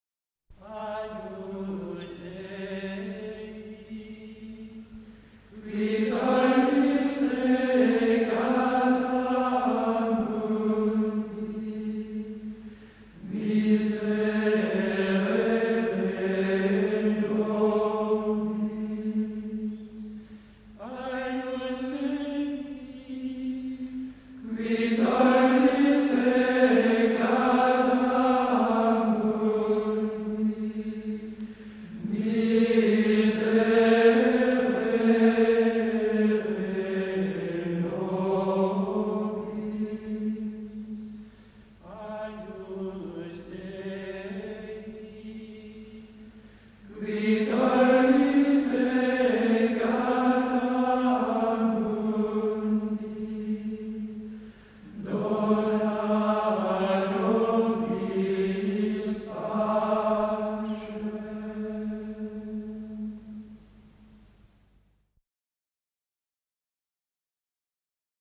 • gloria grégorien sanctus agnus
Voici un des Agnus Dei grégoriens les plus simples et les plus syllabiques, les plus mystiques aussi pourtant, grâce à l’emprunt de sa mélodie au 4e mode.
Les première et troisième invocations sont identiques et la seconde présente une certaine variante mais qui ne sort pas de l’atmosphère générale de la pièce, très simple, très contemplative aussi.
C’est sur le La que culmine cet Agnus Dei plutôt grave et mystérieux.
Le quasi syllabisme de cette formule, lui donne un caractère un peu plus aérien, léger, joyeux.
Ce miserére nobis doit dont être plus intense que le reste, plus ardemment suppliant.
Agnus-10-Solesmes.mp3